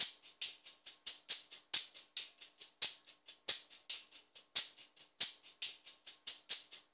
UTC SHKRS -L.wav